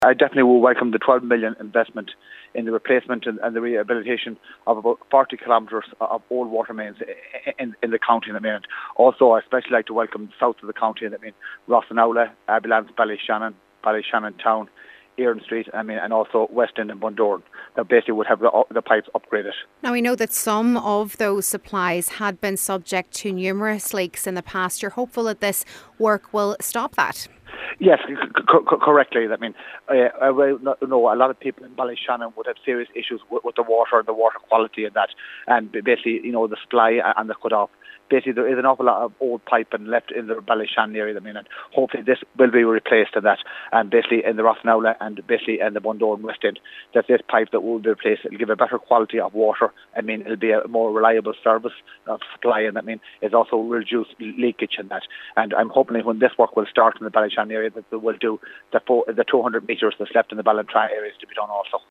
Cathaorileach of the Donegal Municipal District Cllr Michael Naughton says once complete, the work will make a huge difference :